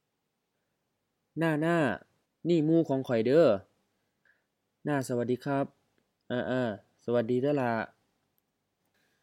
BCF03 Introducing someone else to others — Dialogue B